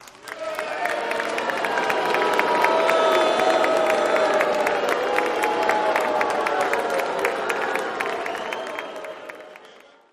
Crowd Boo, Yeah Intro Sensei